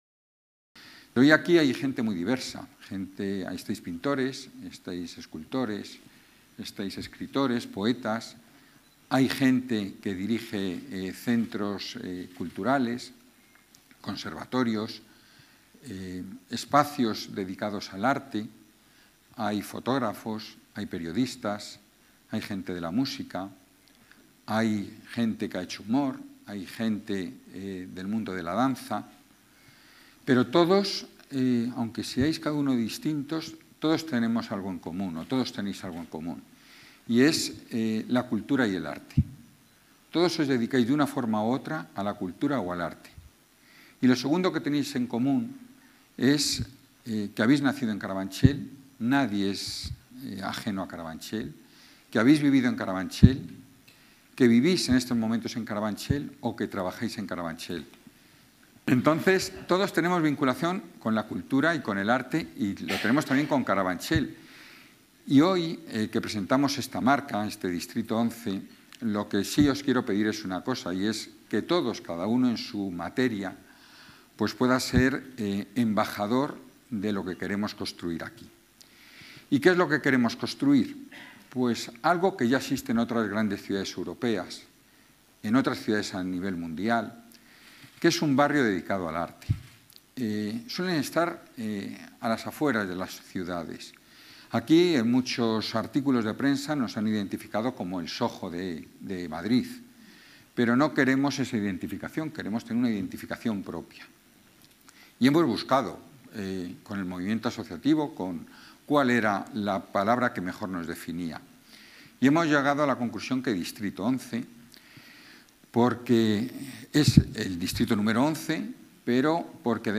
Nueva ventana:Declaraciones del concejal de Carabanchel, Carlos Izquierdo, en la presentación de Distrito 11